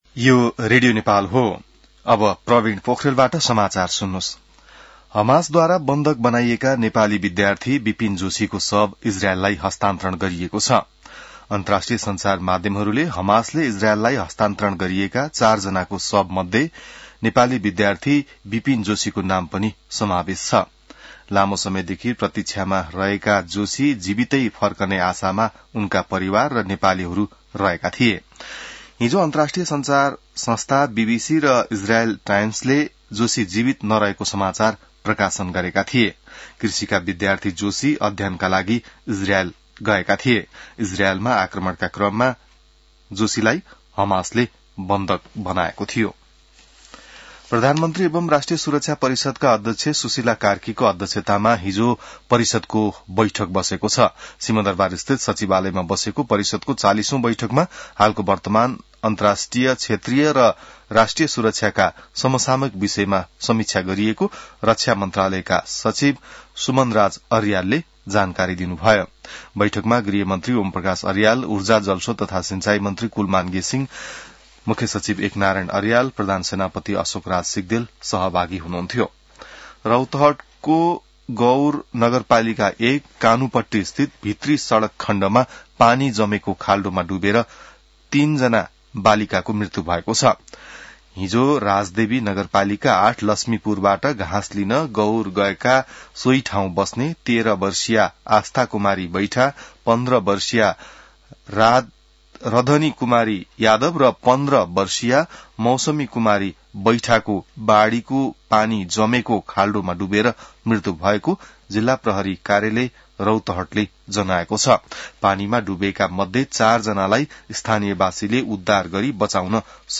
An online outlet of Nepal's national radio broadcaster
बिहान ६ बजेको नेपाली समाचार : २८ असोज , २०८२